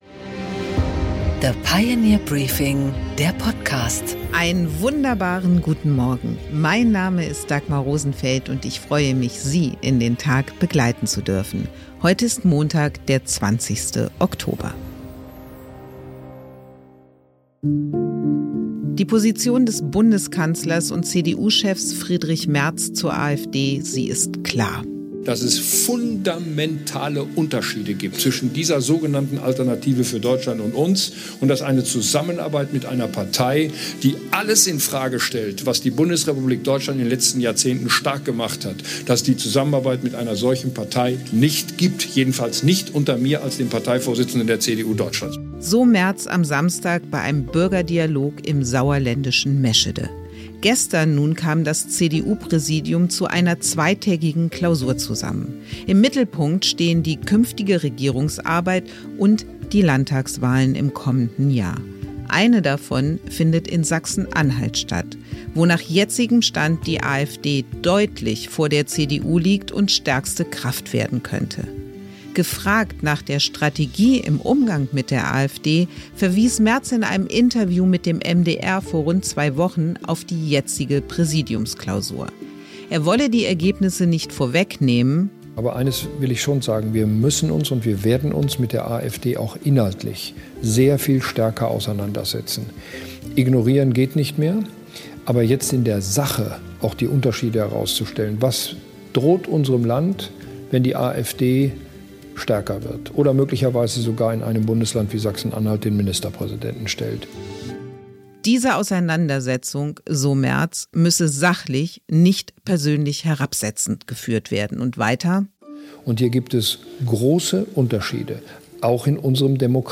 Dagmar Rosenfeld präsentiert das Pioneer Briefing
Im Gespräch: Herbert Reul, NRW-Innenminister (CDU), fordert Ehrlichkeit und Mut in der CDU-Politik, um das Vertrauen der Mitte zurückzugewinnen und der AfD auf Basis von Anstand die Stirn zu bieten.